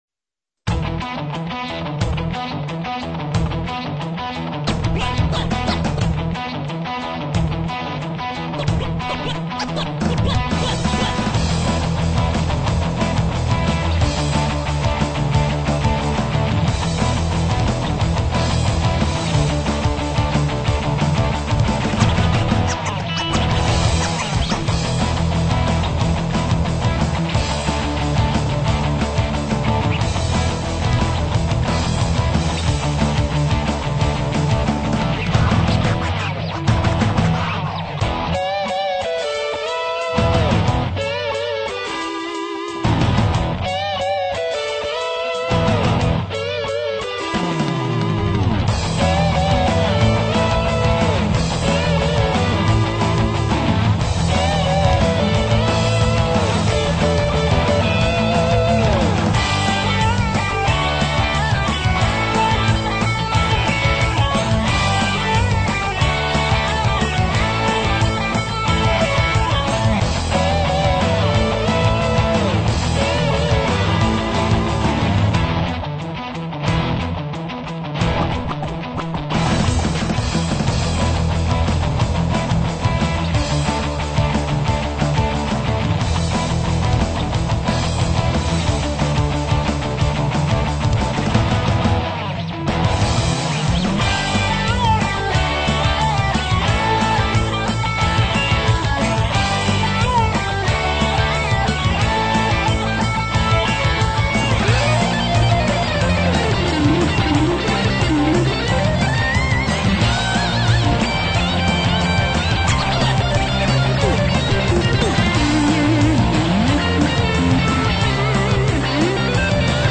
Um pouco de musica de arcade para descontrair;